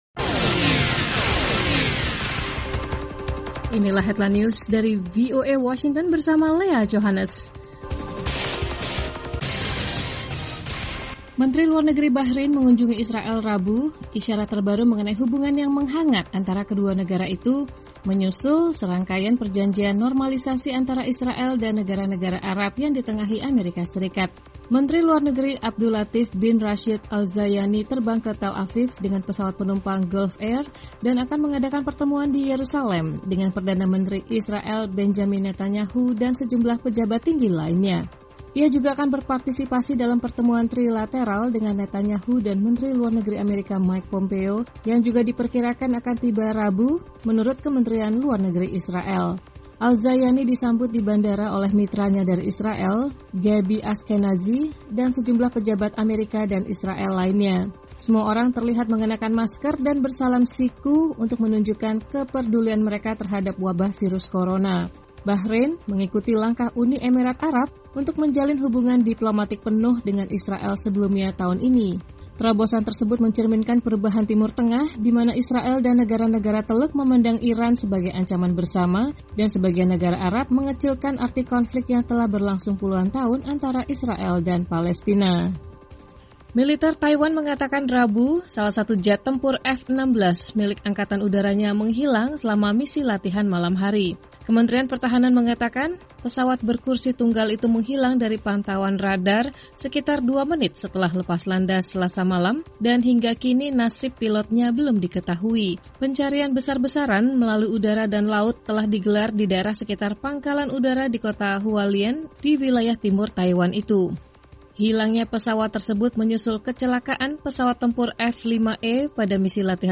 Simak berita terkini langsung dari Washington dalam Headline News, bersama para penyiar VOA, menghadirkan perkembangan terakhir berita-berita internasional.